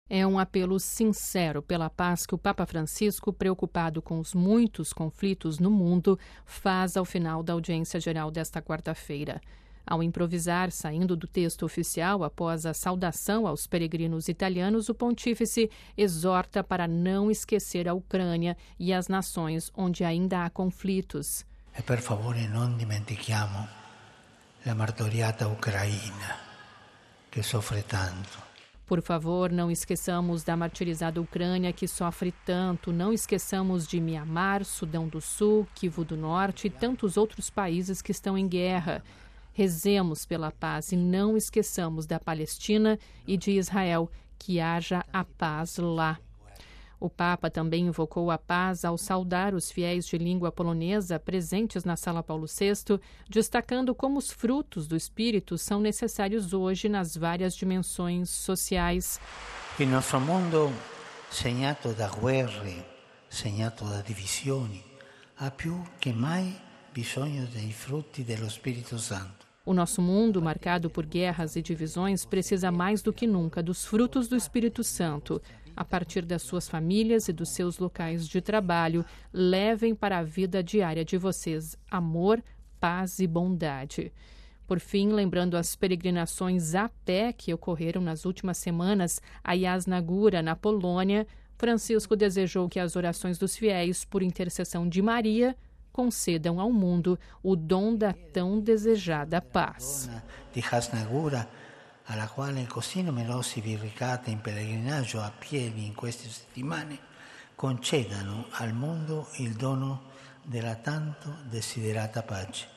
Ouça a reportagem com a voz do Papa e compartilhe
É um apelo sincero pela paz que o Papa Francisco, preocupado com os muitos conflitos no mundo, faz ao final da Audiência Geral desta quarta-feira (21/08).